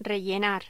Locución: Rellenar
Sonidos: Voz humana